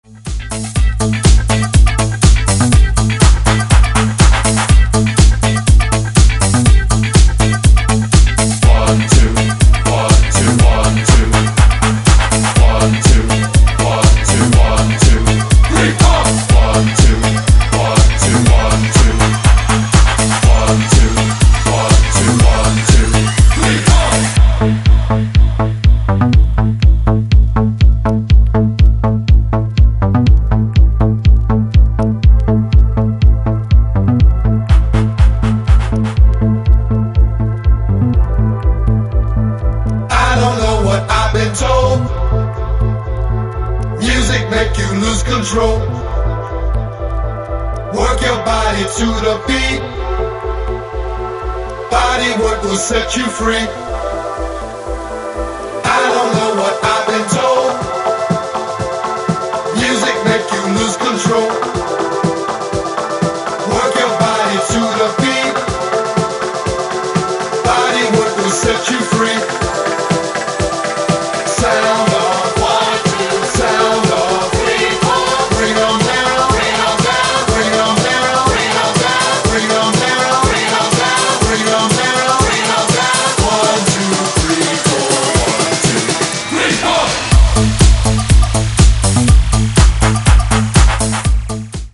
ブギーでソウルフルなディスコ、ファンク、ハウス、を散りばめたグレイト過ぎる好内容です！